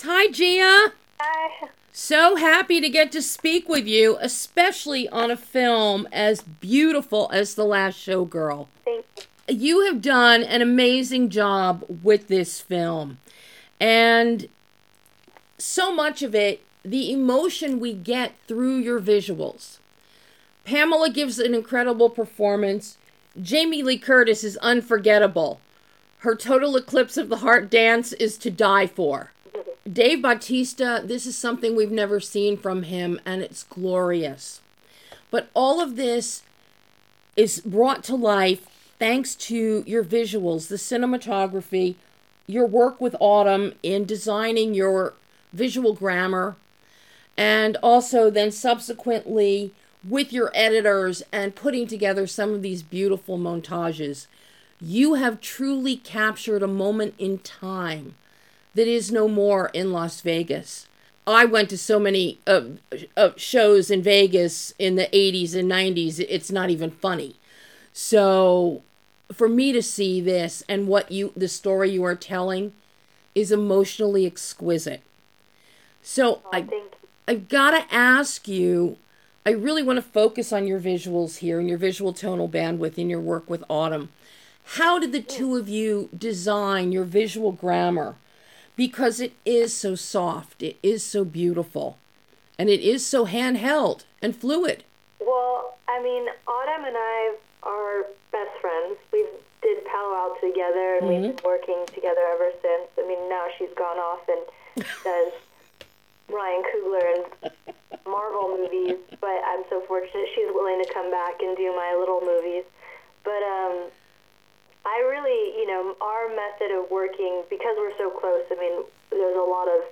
Director GIA COPPOLA dazzles us with 16mm, Las Vegas, and THE LAST SHOWGIRL – Exclusive Interview